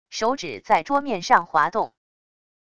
手指在桌面上滑动wav音频